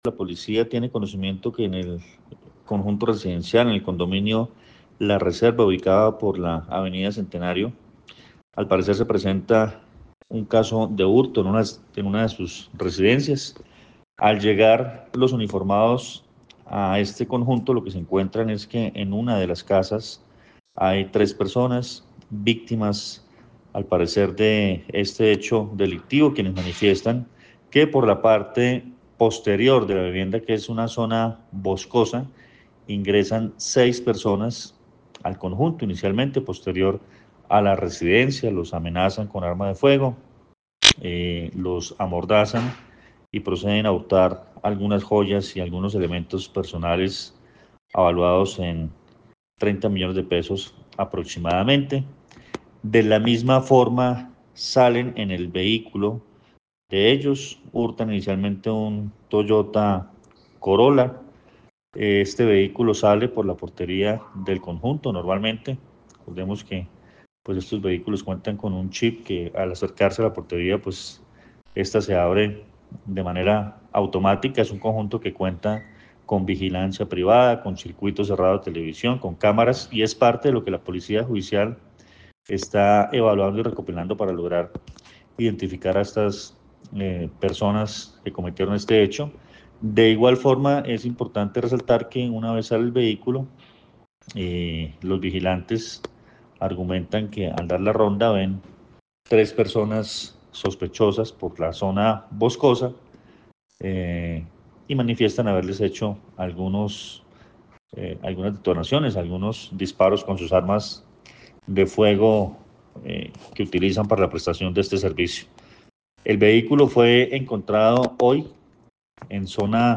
Coronel Luis Fernando Atuesta, comandante de la Policía del Quindío